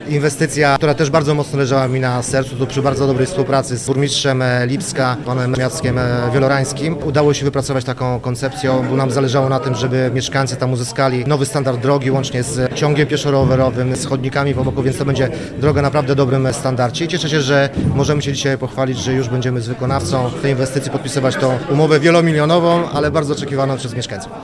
– mówił wicemarszałek województwa mazowieckiego Rafał Rajkowski.